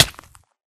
hurtflesh1.ogg